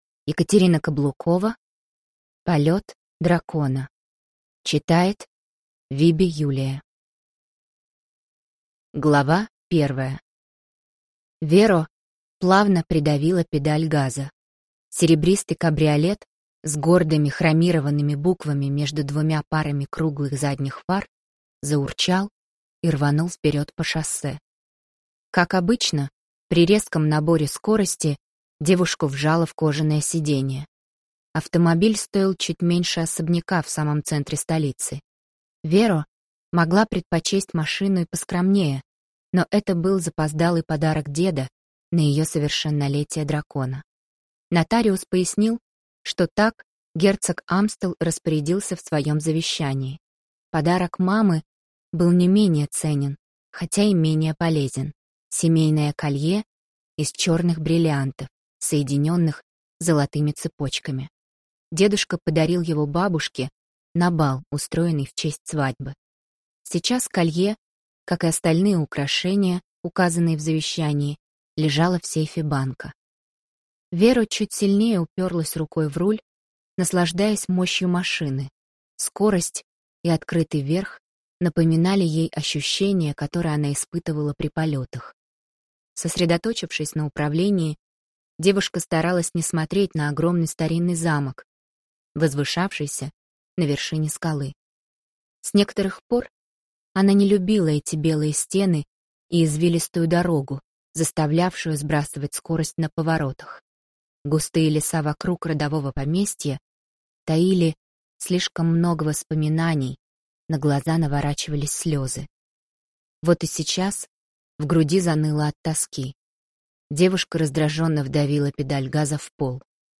Аудиокнига Полет дракона | Библиотека аудиокниг